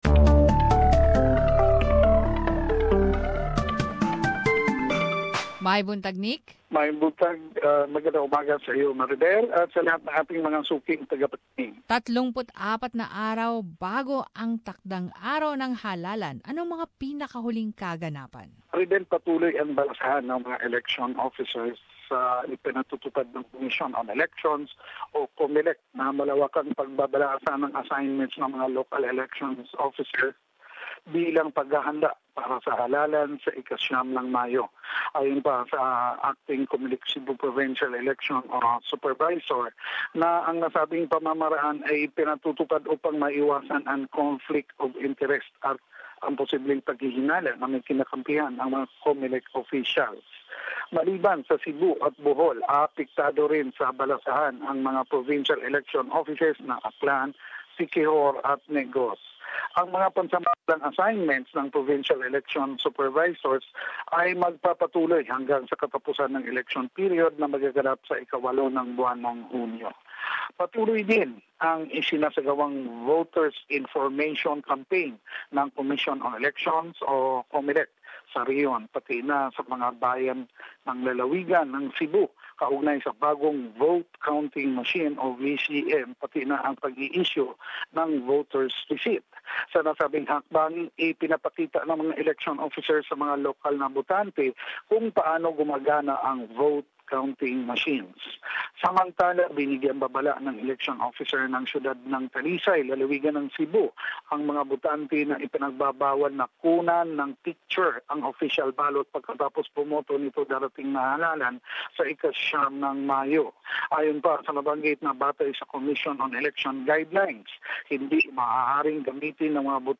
Summary of latest news from the region